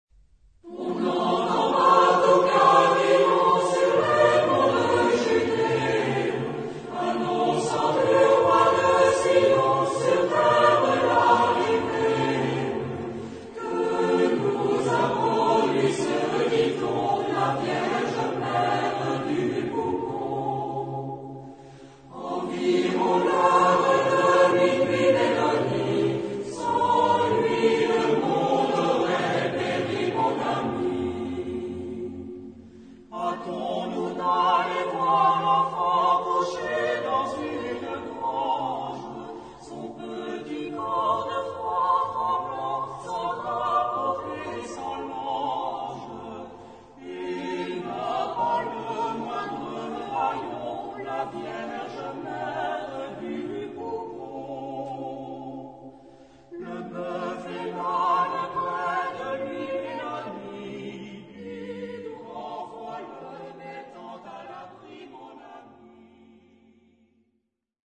Genre-Stil-Form: Volkstümlich ; Weihnachtslied
Chorgattung: SATB  (4 gemischter Chor Stimmen )
Tonart(en): F-Dur